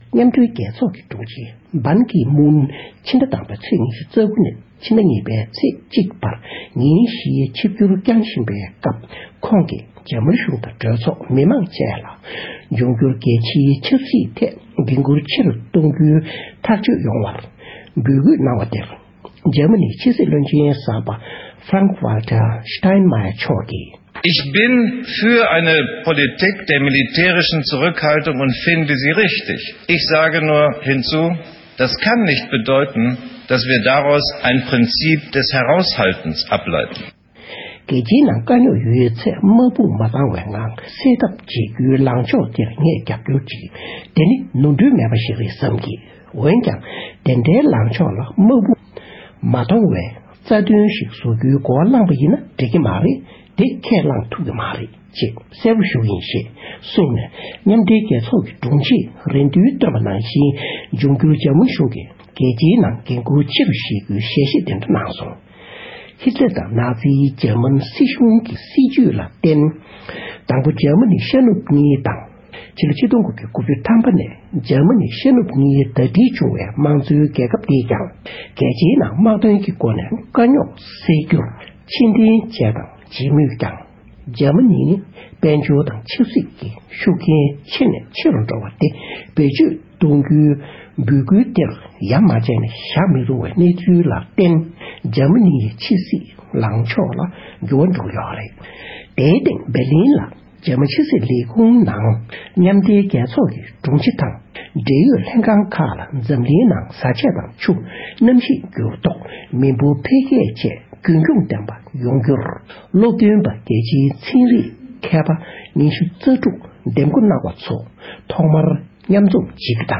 དེའི་སྐོར་ང་ཚོའི་གསར་འགོད་པ